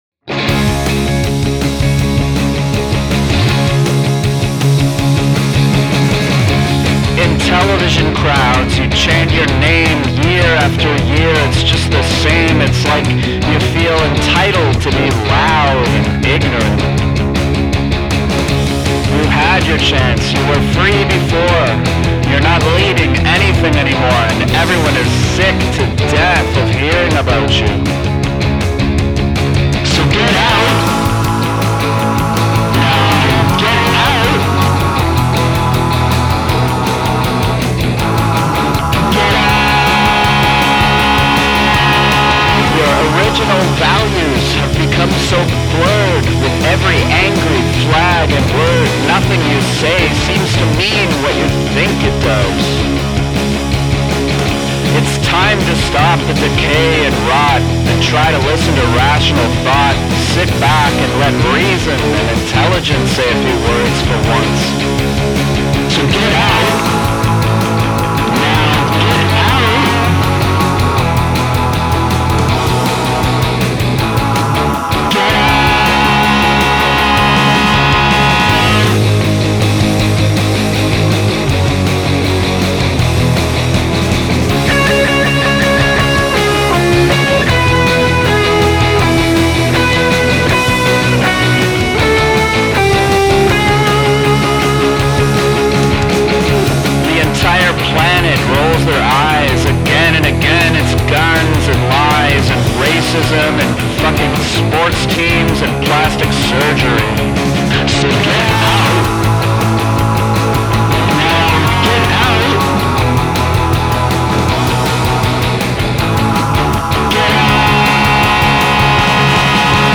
Like the vocal.